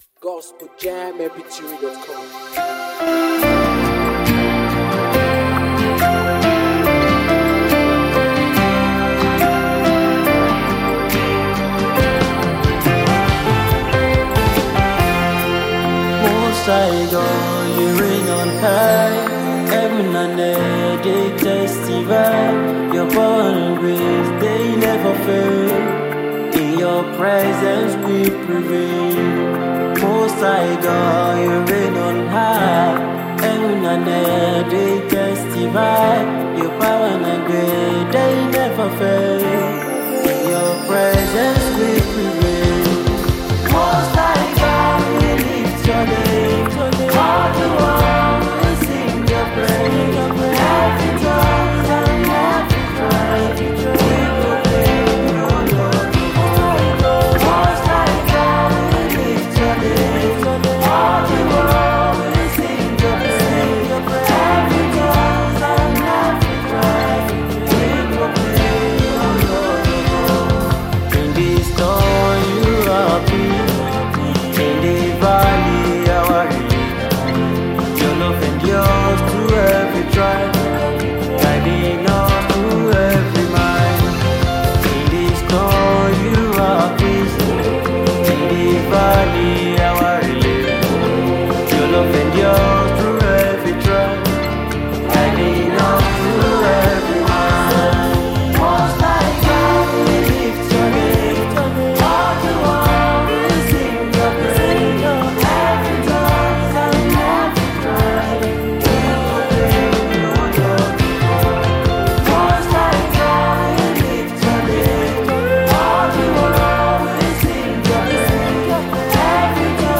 contemporary worship
is a powerful and moving worship song